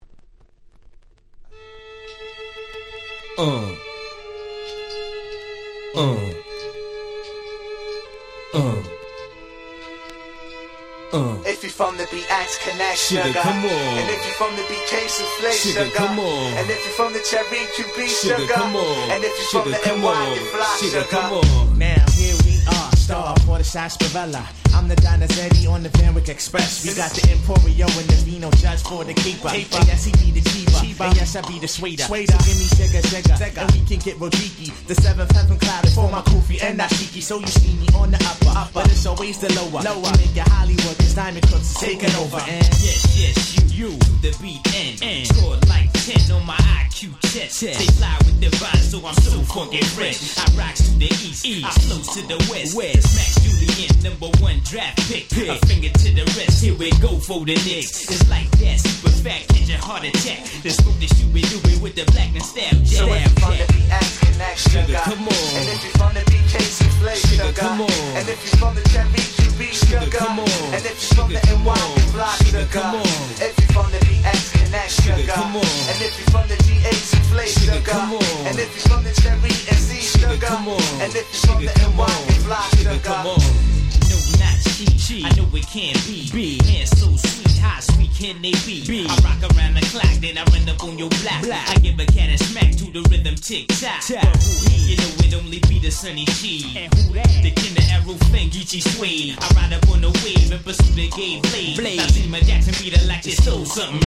97' Smash Hit Hip Hop !!
曲が始まってしまうと普通のバージョンとそこまで違いはございません。
Boom Bap ブーンバップ